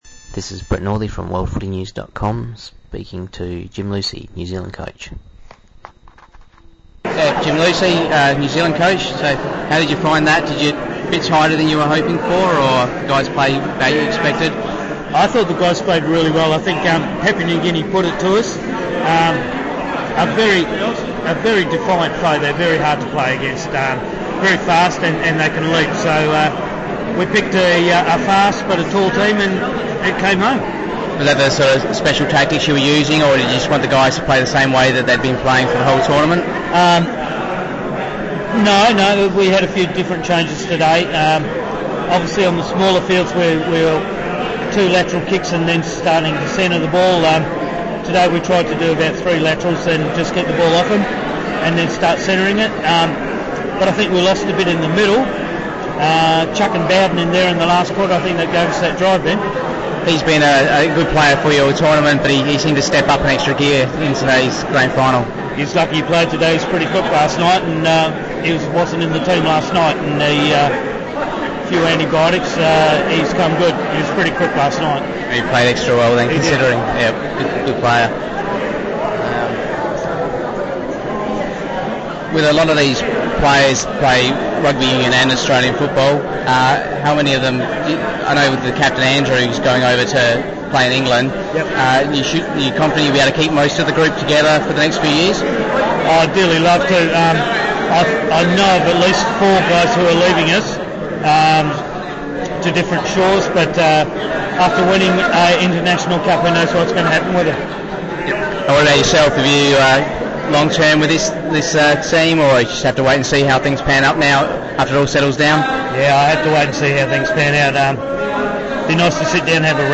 Post Grand Final interview